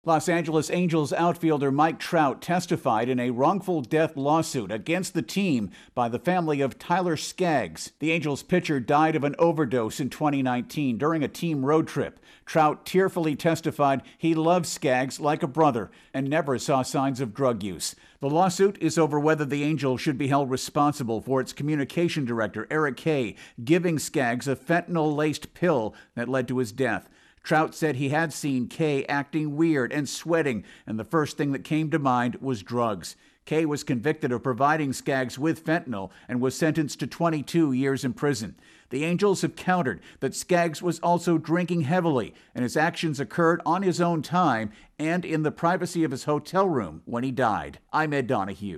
reports on testimony from a baseball player.